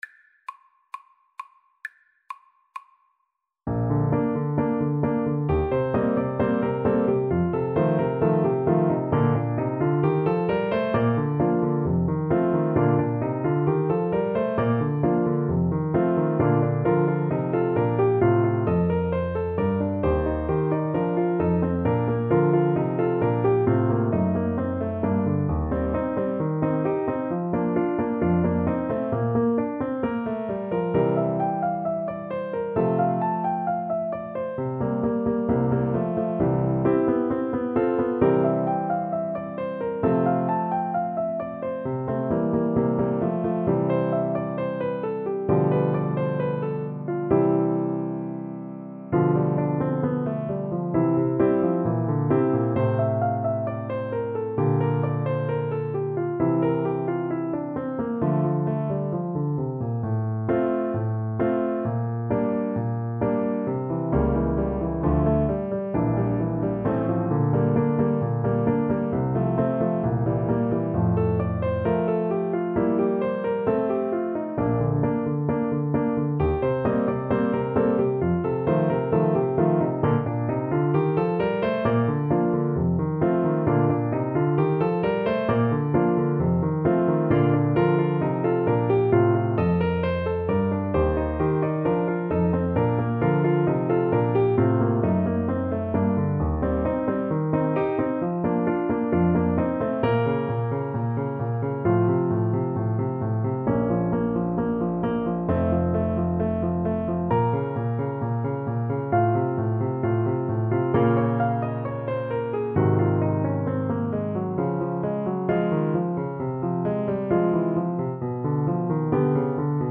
Play (or use space bar on your keyboard) Pause Music Playalong - Piano Accompaniment Playalong Band Accompaniment not yet available transpose reset tempo print settings full screen
Bb major (Sounding Pitch) C major (Trumpet in Bb) (View more Bb major Music for Trumpet )
=132 Allegro assai (View more music marked Allegro)
Classical (View more Classical Trumpet Music)